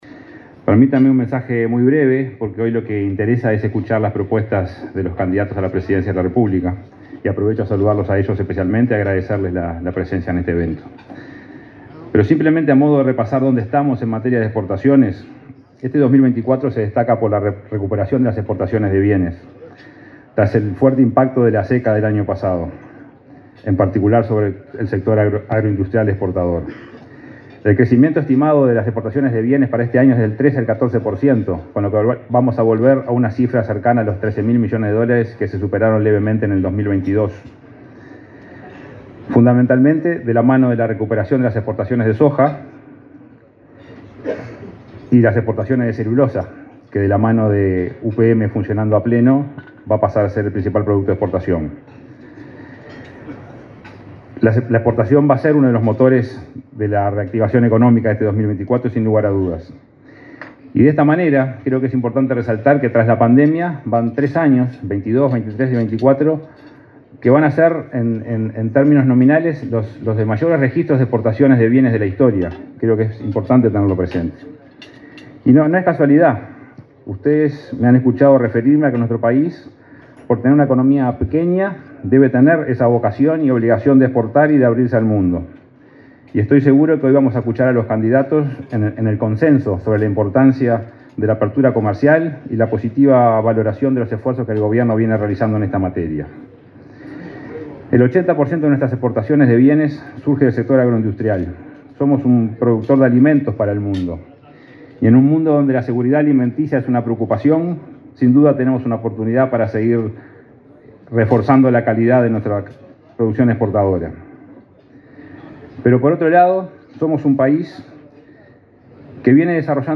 Palabras del presidente del BROU, Salvador Ferrer
Palabras del presidente del BROU, Salvador Ferrer 30/07/2024 Compartir Facebook X Copiar enlace WhatsApp LinkedIn El presidente del Banco de la República (BROU), Salvador Ferrer, se expresó en la celebración del Día del Exportador, este martes 30 en el Club de Golf de Montevideo.